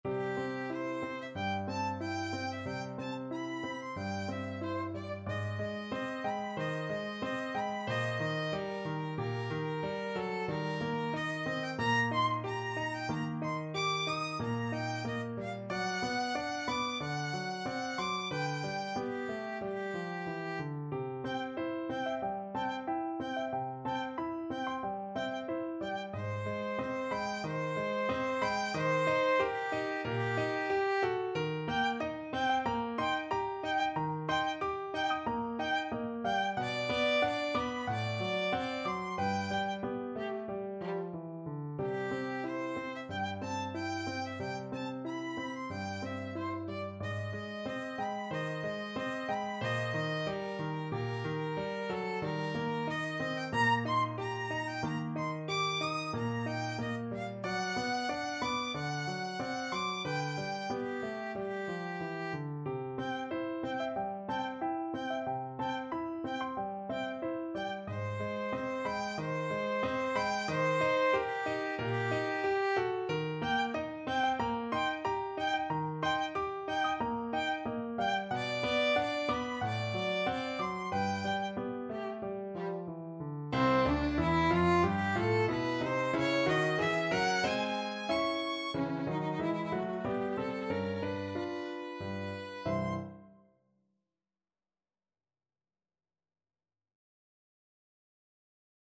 Andantino =92 (View more music marked Andantino)
Classical (View more Classical Violin Music)